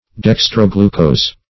dextroglucose - definition of dextroglucose - synonyms, pronunciation, spelling from Free Dictionary
Dextroglucose \Dex`tro*glu"cose`\, n. [Dextro- + glucose.]